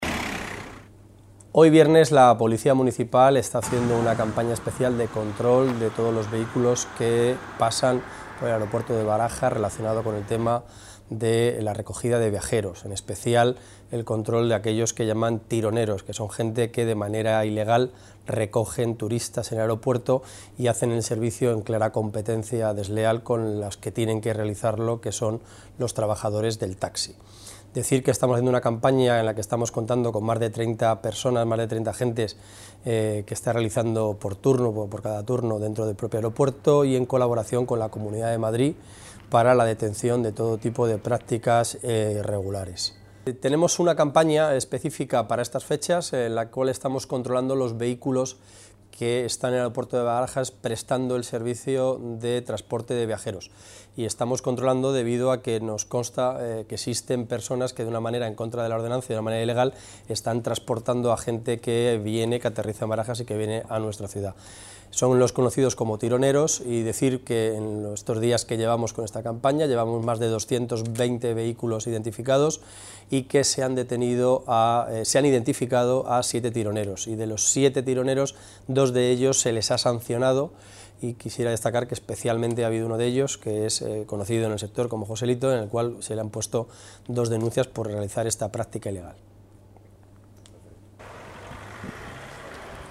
Nueva ventana:Declaraciones de Emilio García Grande, coordinador de Seguridad y Emergencias